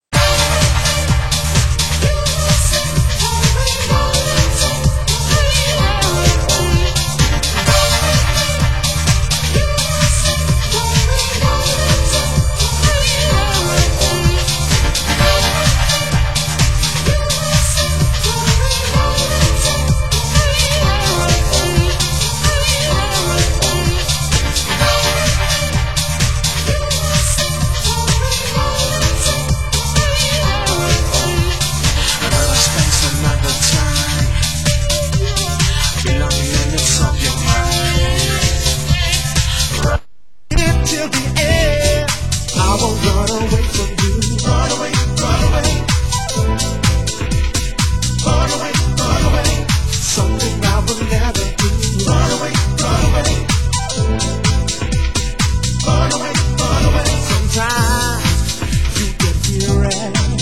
Format: Vinyl 12 Inch
Genre: UK House